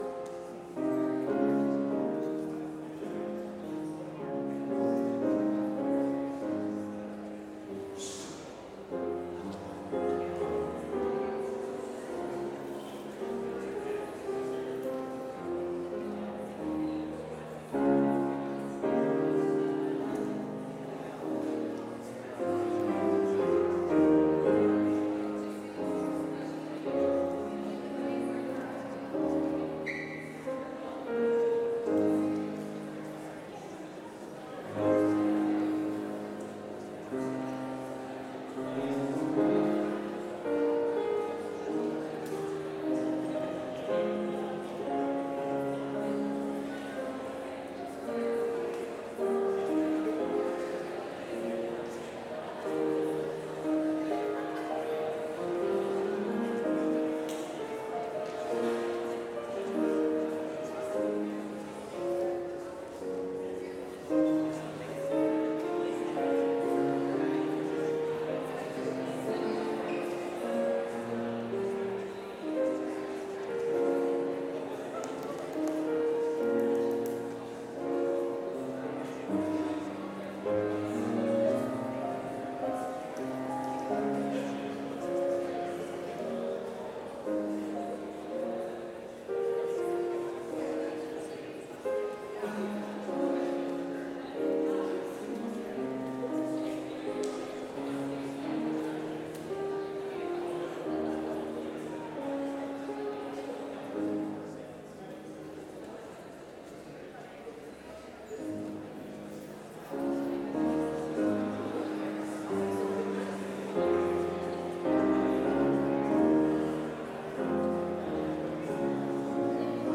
Complete service audio for Chapel - Thursday, August 29, 2024